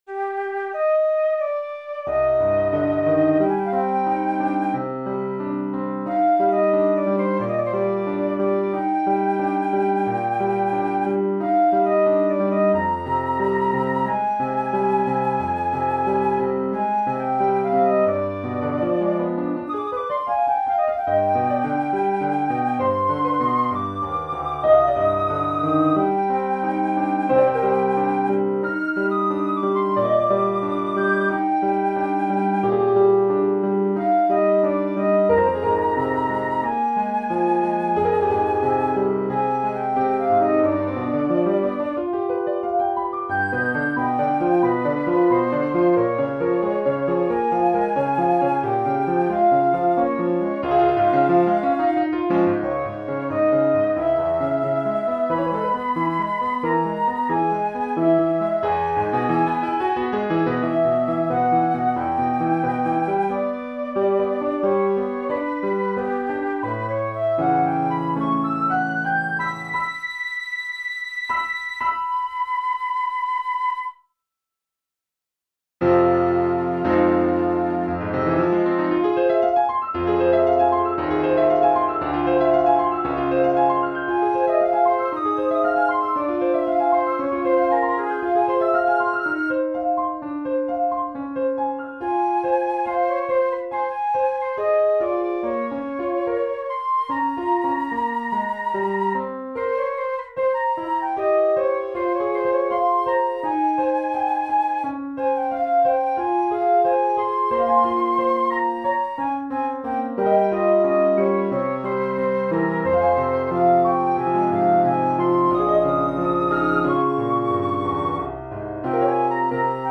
Untitled_flute_sonata_fuck_midis.mp3